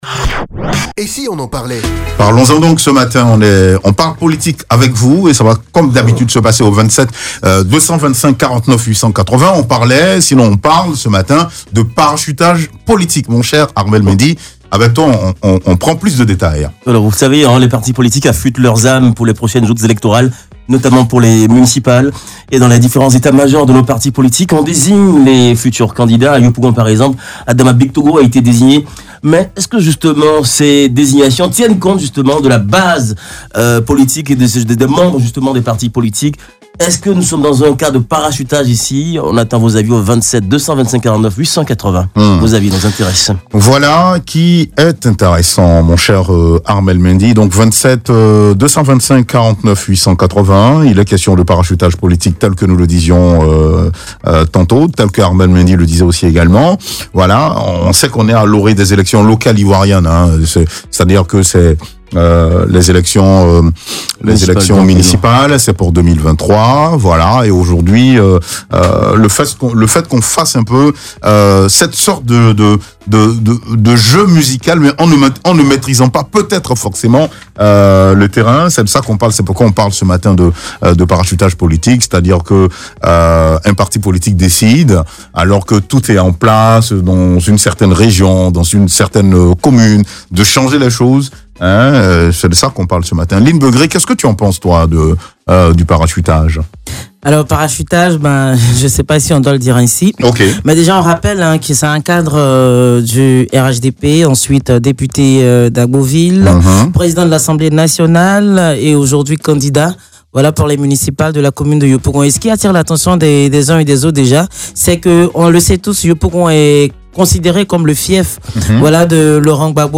Peut-on parler de parachutage politique ? Les auditeurs donnent leurs avis.